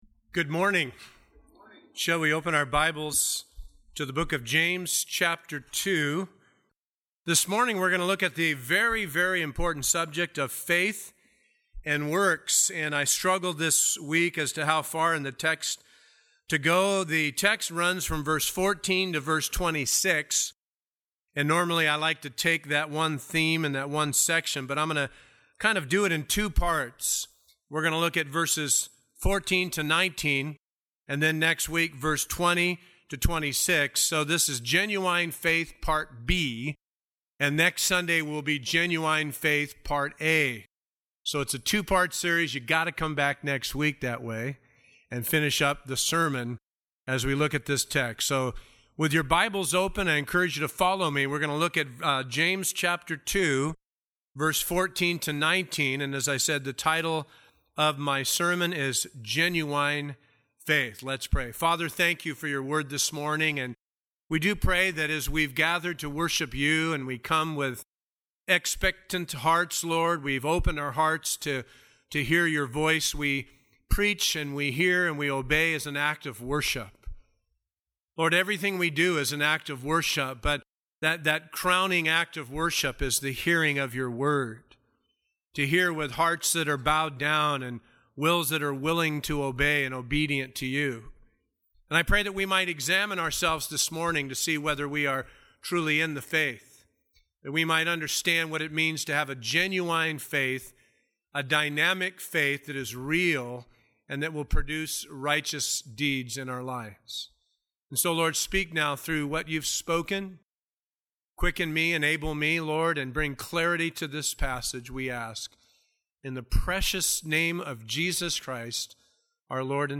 2010 Share sermon Sermon Scripture Reference 2:14 What does it profit